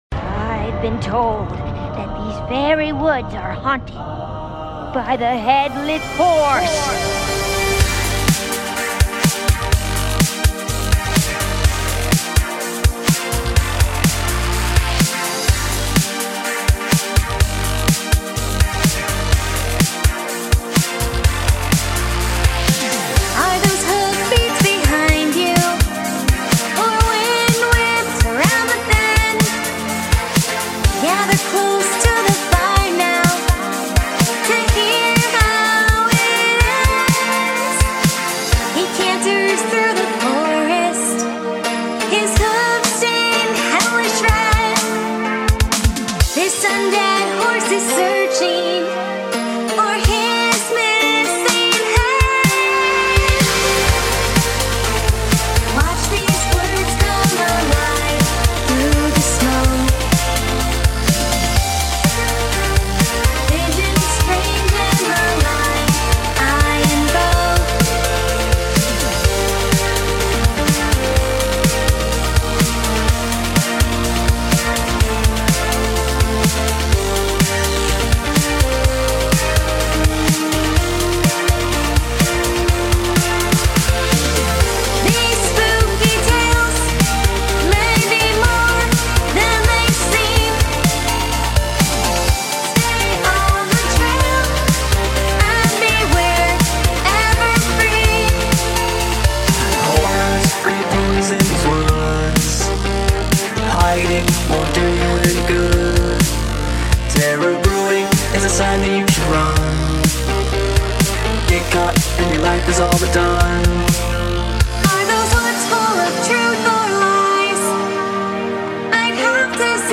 A very sp00ktacular Halloween themed song for you all.
BPM - 125
Genre - Electro